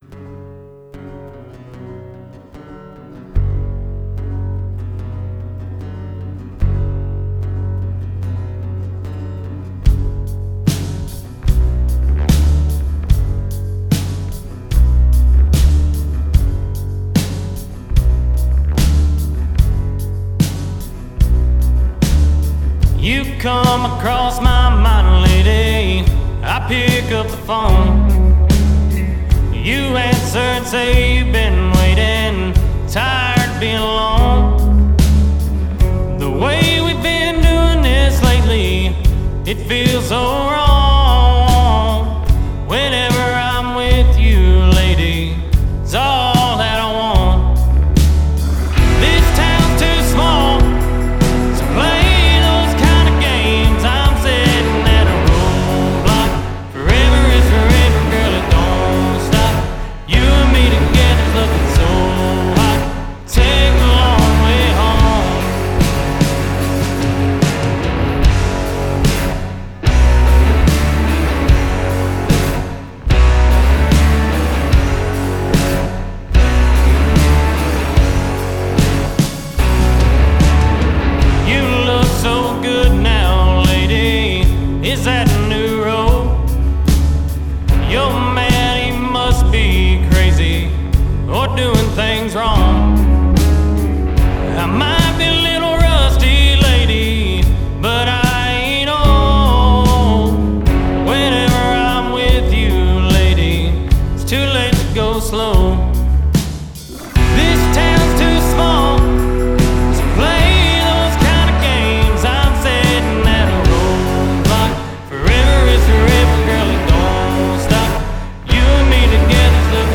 darker tune